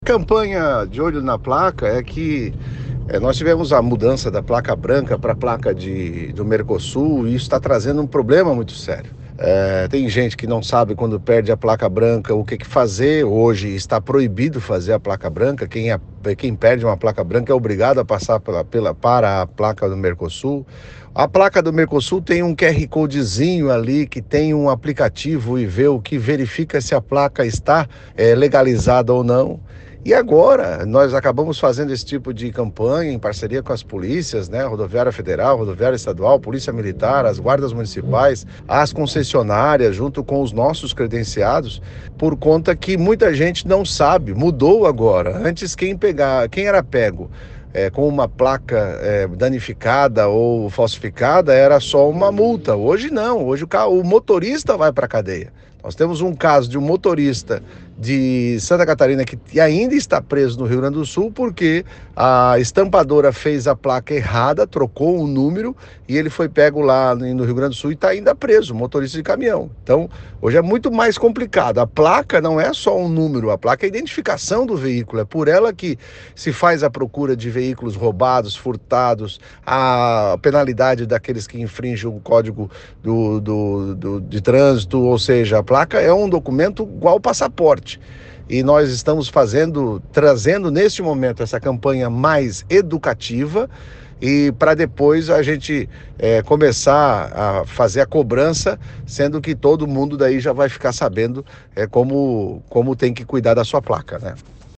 O presidente do Detran/SC, Kennedy Nunes, reforça a importância de conferir se a placa do veículo está regular, observar a autenticidade e se tem registro já que existem infrações de natureza gravíssima: